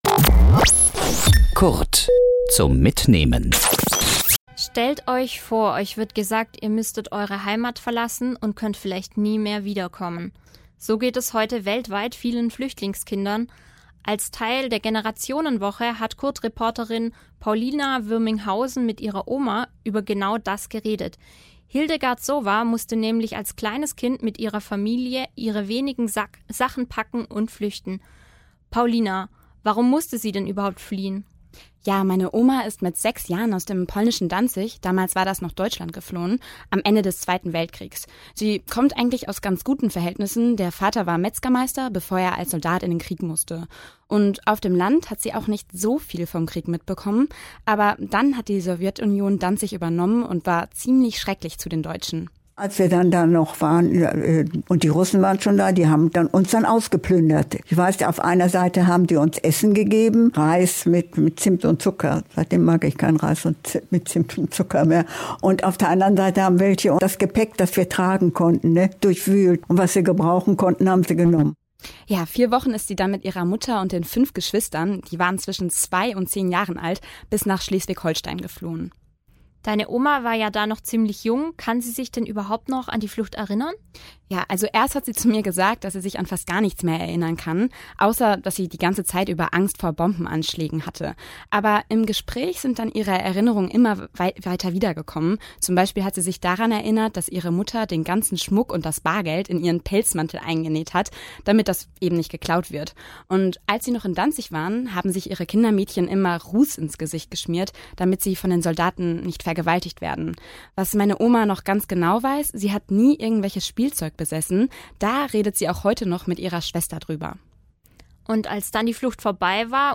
Serie: Kollegengespräch